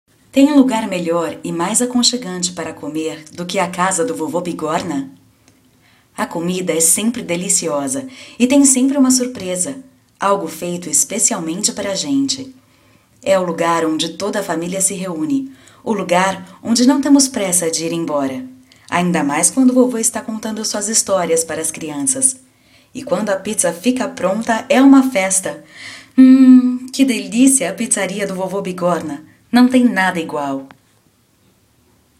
Brazilian actress, voice over, dubbing, singer
Sprechprobe: Industrie (Muttersprache):